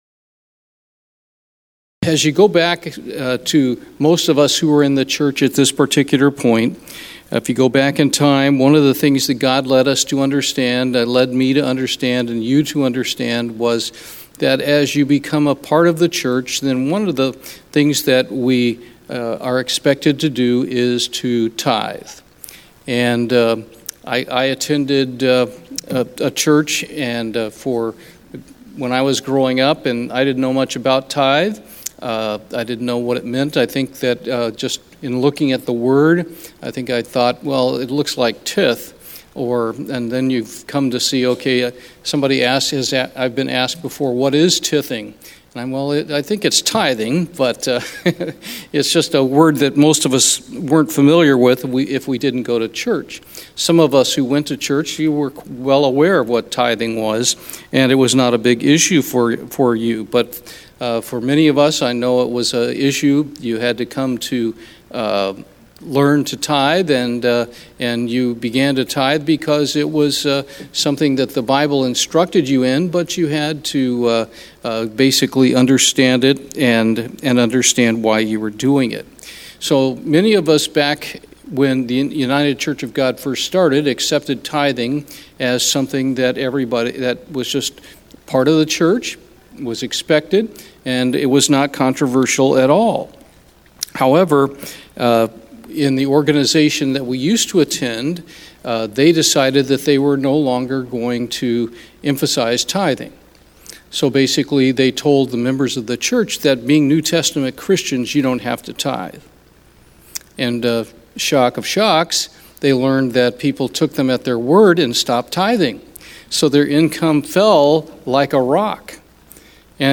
This sermon is the first of a two part series.